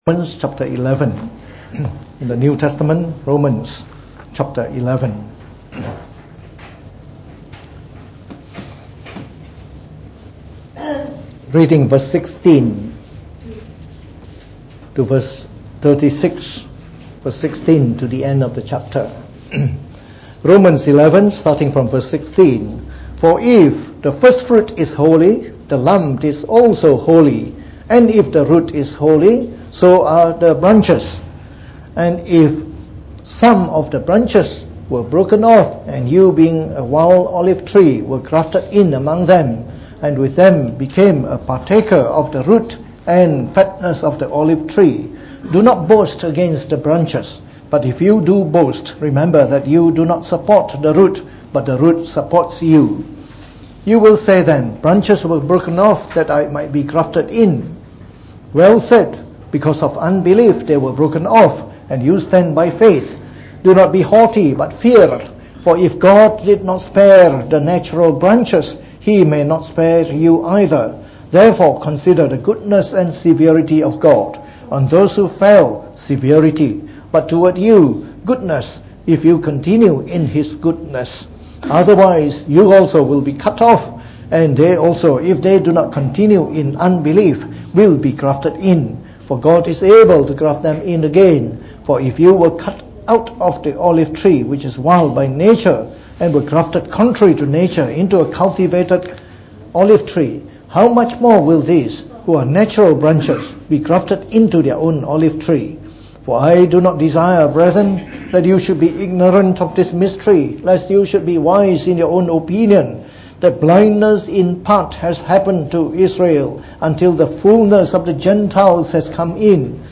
Preached on the 23rd of September 2018.